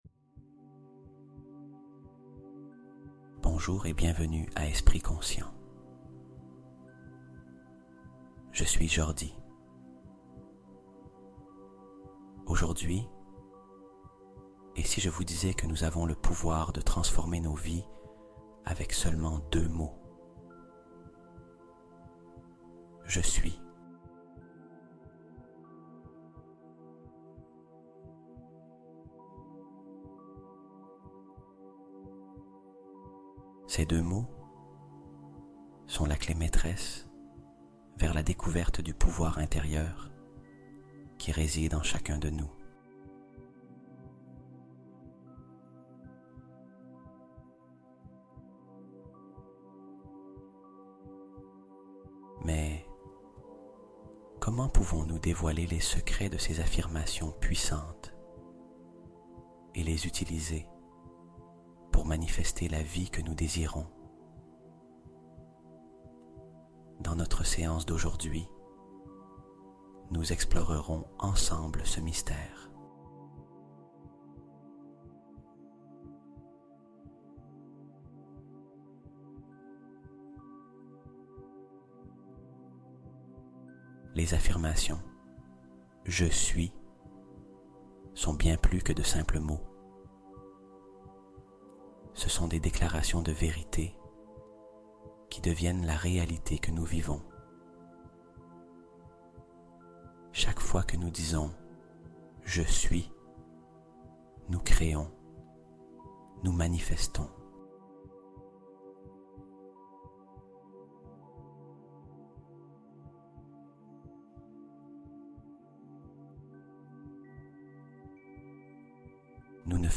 Puissance du "Je Suis" : Méditation profonde pour ancrer sa confiance